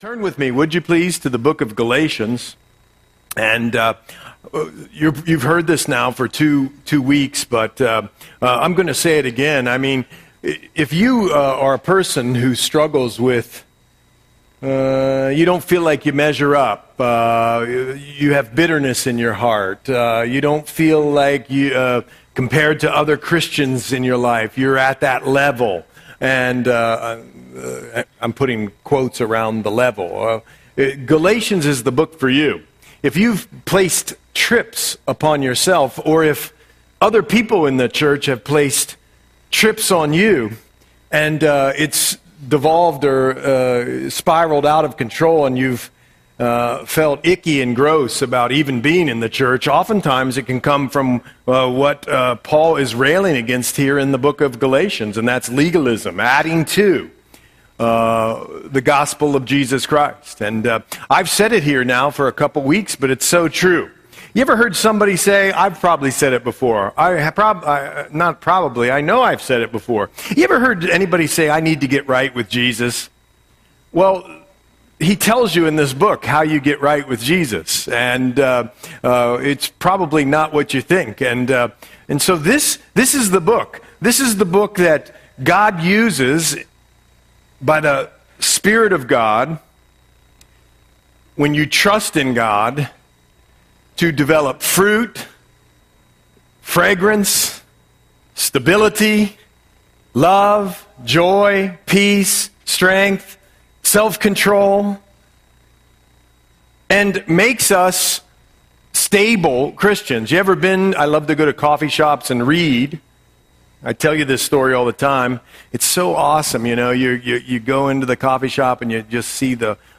Audio Sermon - June 8, 2025